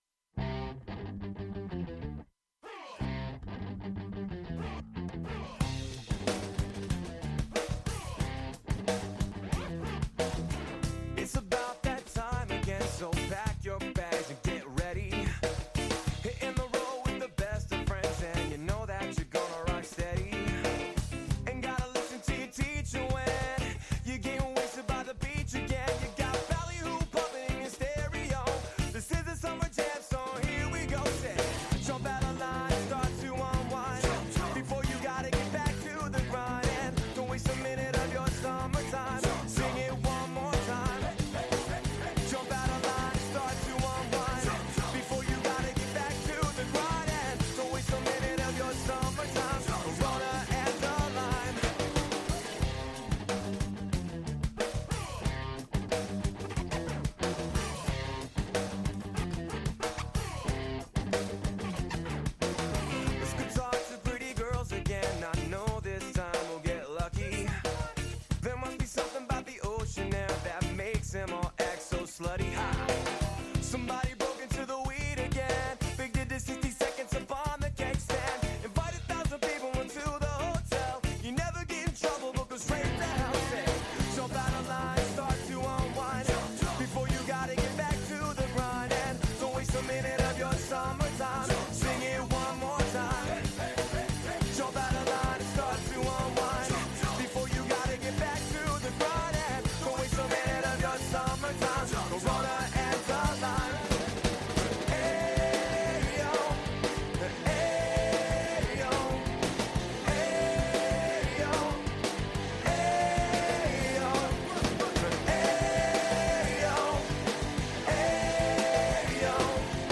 Facebook Twitter Headliner Embed Embed Code See more options After navigating a marathon riddled Brooklyn landscape, the boys arrive at the studio just in time for an interview with hometown punk band: Up For Nothing. Listen in as we talk about the band's history, their recent Fest 15 appearance, as well as their upcoming final shows.